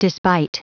Prononciation du mot despite en anglais (fichier audio)